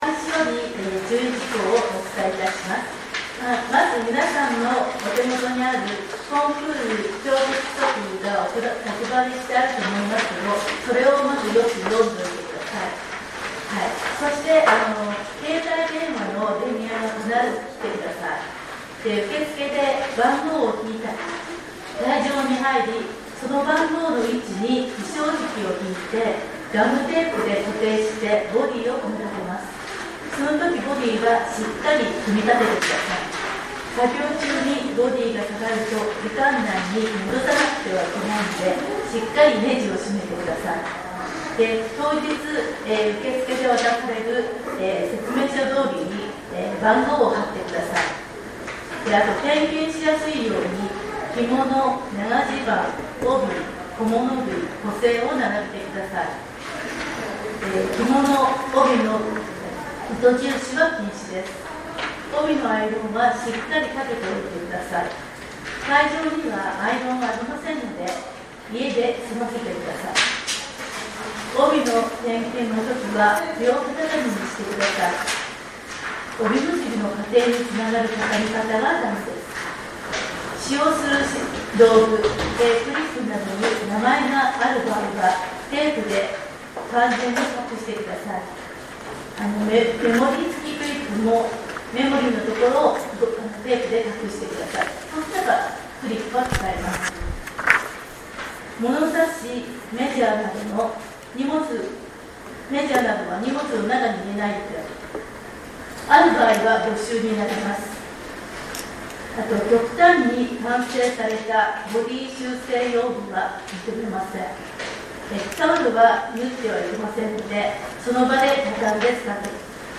競技規則（第66回大会／2025年5月28日説明会開催分）
選手説明会音声データ（留袖着付競技）